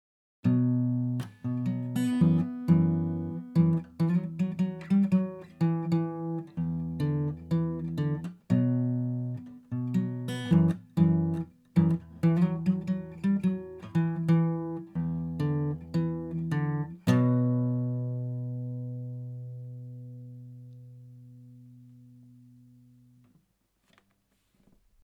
Gezupfte Akustikgitarre in etwa 20 cm Abstand: